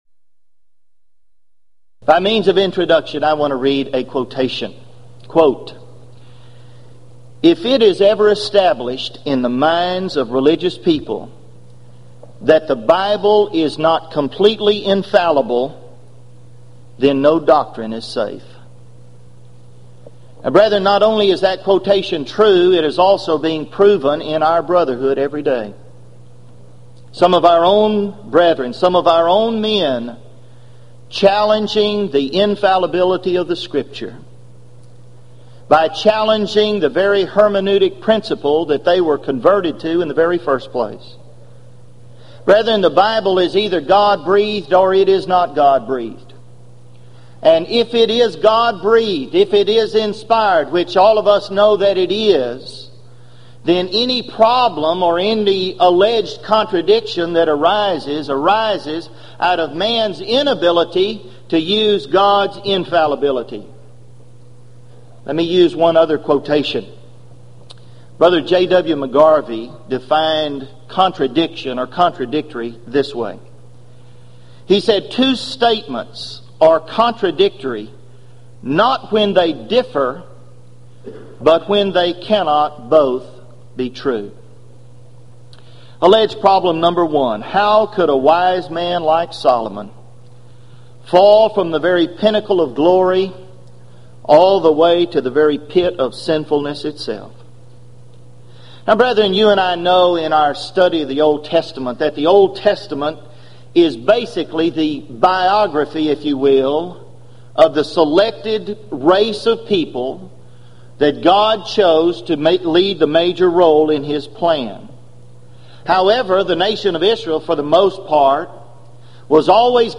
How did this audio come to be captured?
Event: 1995 Gulf Coast Lectures